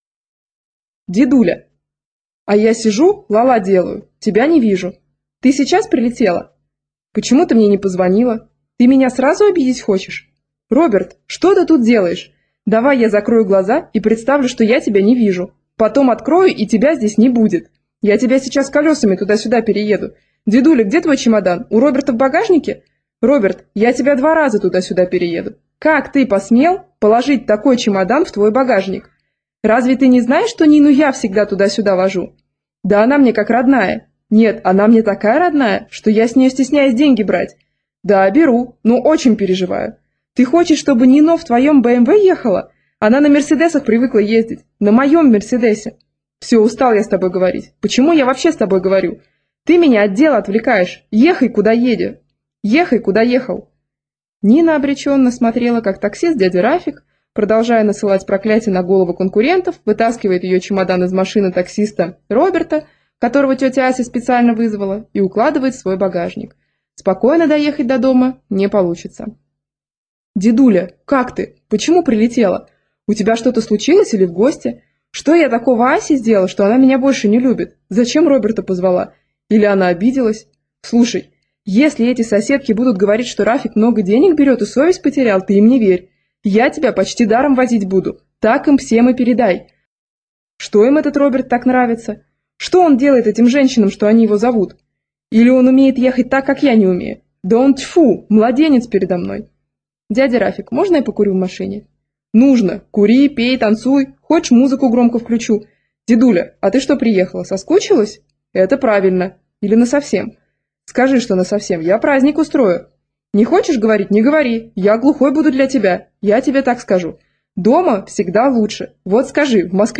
Аудиокнига Шушана, Жужуна и другие родственники - купить, скачать и слушать онлайн | КнигоПоиск